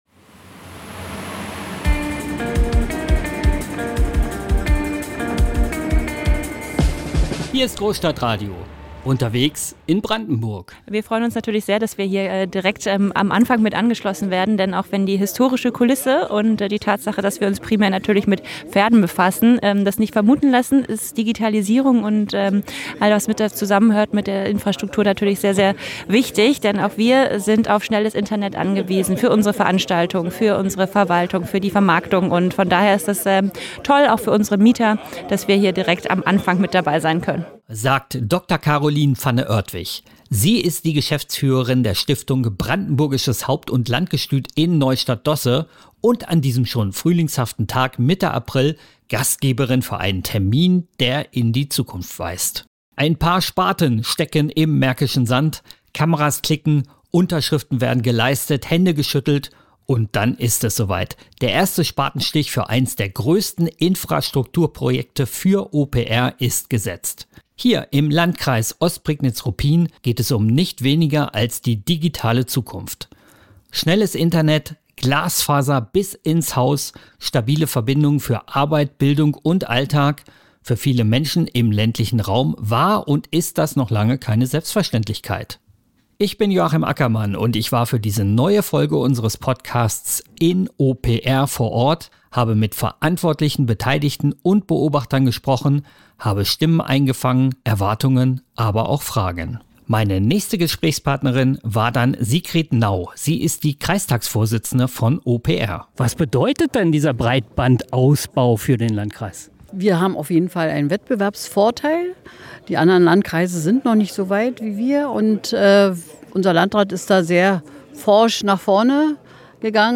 Beschreibung vor 1 Tag Ein Spatenstich im märkischen Sand – und doch geht es um weit mehr als nur ein Bauprojekt beim Glasfaserausbau in Ostprignitz-Ruppin.